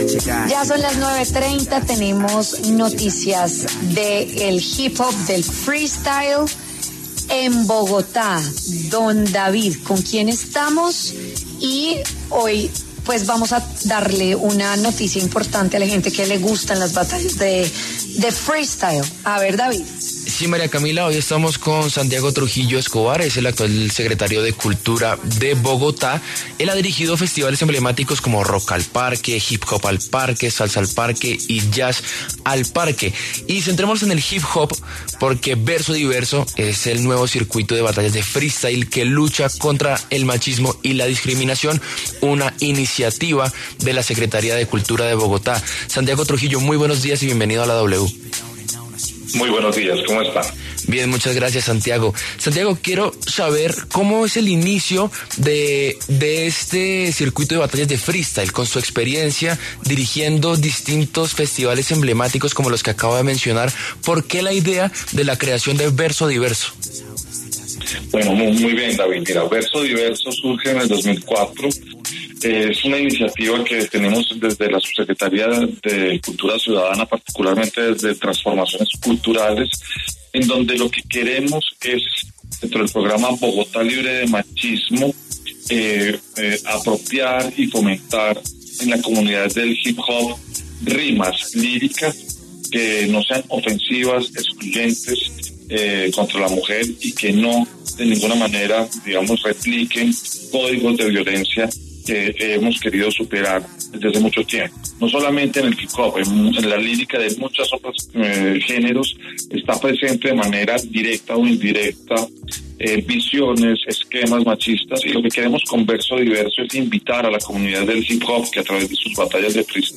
Santiago Trujillo Escobar, secretario de Cultura, Recreación y Deporte de Bogotá, dio detalles en W Fin De Semana sobre ‘Verso Diverso’, el escenario con el que buscan que se derrote al machismo en las batallas de freestyle.